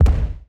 EXPLDsgn_Explosion Impact_03_SFRMS_SCIWPNS.wav